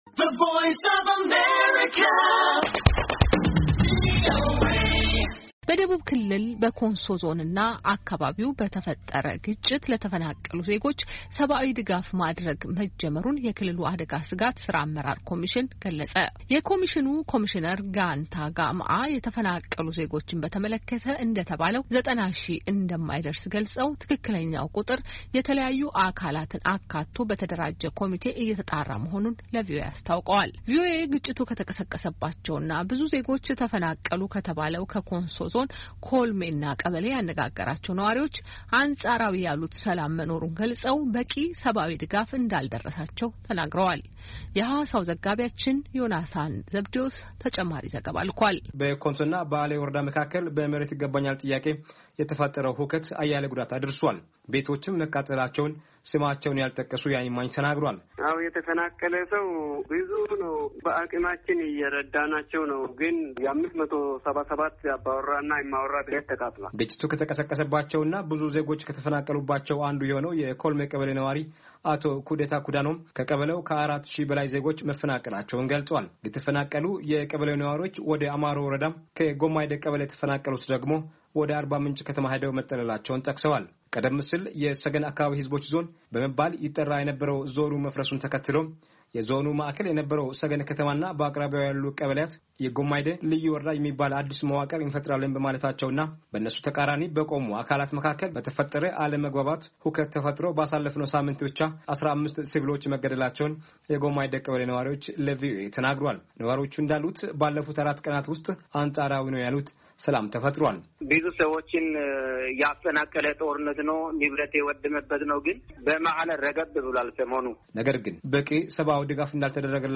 የኮሚሽኑ ኮሚሽነር ጋንታ ጋምአ የተፈናቀሉ ዜጎችን በተመለከተ እንደተባለው 90ሺህ እንደማይደርስ ገልጸው፣ ትክክለኛው ቁጥር የተለያዩ አካላትን አካቶ በተደራጀ ኮሚቴ እየተጣራ መሆኑን ለቪኦኤ አስታውቀዋል።
ቪኦኤ ግጭቱ ከተቀሰቀባቸው እና ብዙ ዜጎች ተፈናቀሉ ከተባለው ከኮንሶ ዞን ኮልሜና ቀበሌ ያነጋገራቸው ነዋሪዎች አንፃራዊ ያሉት ሰላም መኖሩን ገልፀው በቂ ሰብዓዊ ድጋፍ እንዳልደረሰላቸው ተናግረዋል።